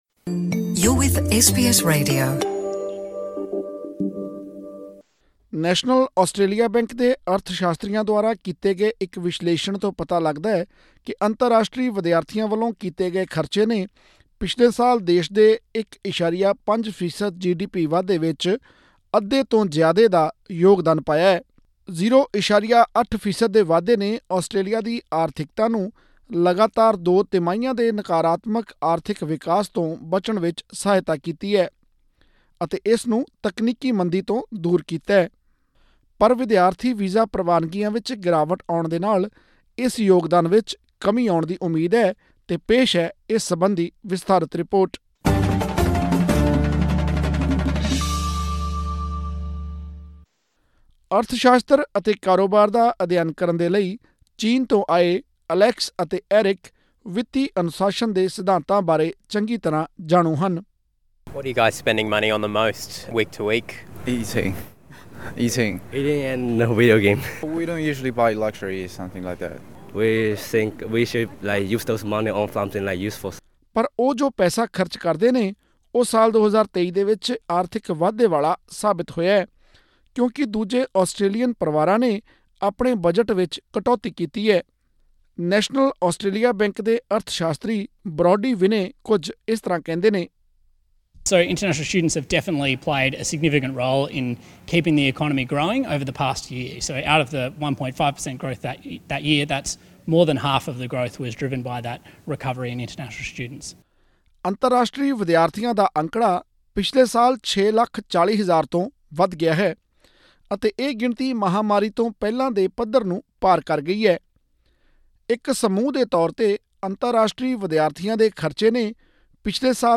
ਆਸਟ੍ਰੇਲੀਆ ਵਿੱਚ ਅੰਤਰਰਾਸ਼ਟਰੀ ਵਿਦਿਆਰਥੀਆਂ ਦਾ ਅੰਕੜਾ ਪਿਛਲੇ ਸਾਲ 6,40,000 ਤੋਂ ਵੱਧ ਗਿਆ ਹੈ ਅਤੇ ਇਹ ਗਿਣਤੀ ਮਹਾਂਮਾਰੀ ਤੋਂ ਪਹਿਲਾਂ ਦੇ ਪੱਧਰ ਨੂੰ ਪਾਰ ਕਰ ਗਈ ਹੈ। ਅਰਥ ਸ਼ਾਸਤਰੀਆਂ ਦੁਆਰਾ ਕੀਤੇ ਗਏ ਵਿਸ਼ਲੇਸ਼ਣ ਤੋਂ ਪਤਾ ਲੱਗਦਾ ਹੈ ਕਿ ਅੰਤਰਰਾਸ਼ਟਰੀ ਵਿਦਿਆਰਥੀਆਂ ਵਲੋਂ ਕੀਤੇ ਗਏ ਖਰਚੇ ਨੇ ਪਿਛਲੇ ਸਾਲ ਦੇਸ਼ ਦੇ 1.5% ਜੀਡੀਪੀ ਵਾਧੇ ਵਿੱਚ ਅੱਧੇ ਤੋਂ ਜ਼ਿਆਦੇ ਦਾ ਯੋਗਦਾਨ ਪਾਇਆ ਹੈ। ਵਿਦਿਆਰਥੀ ਵੀਜ਼ਾ ਪ੍ਰਵਾਨਗੀਆਂ ਵਿੱਚ ਗਿਰਾਵਟ ਆਉਣ ਨਾਲ, ਇਸ ਯੋਗਦਾਨ ਵਿੱਚ ਕਮੀ ਅਉਣ ਦੀ ਸੰਭਾਵਨਾ ਵੀ ਬਣੀ ਹੋਈ ਹੈ। ਹੋਰ ਵੇਰਵੇ ਲਈ ਸੁਣੋ ਇਹ ਆਡੀਓ ਰਿਪੋਰਟ…